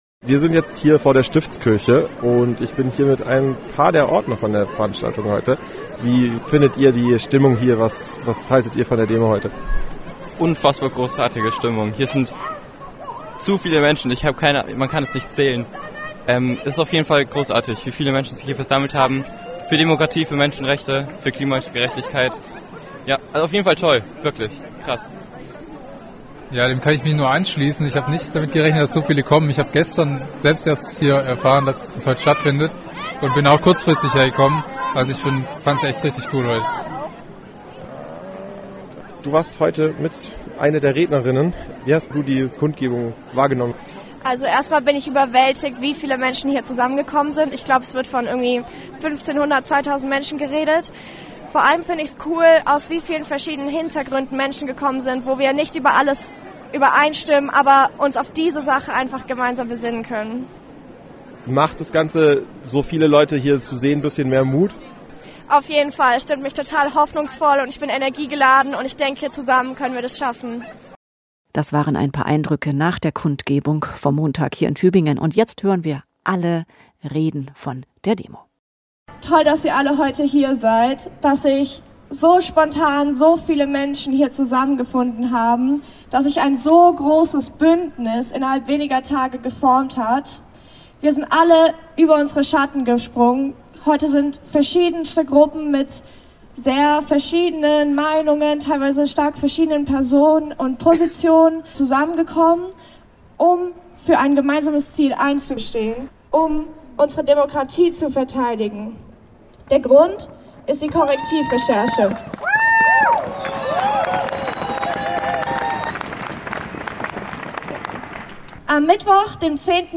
Hier könnt Ihr alle Reden der Kundgebung vom letzten Montag in Tübingen nachhören.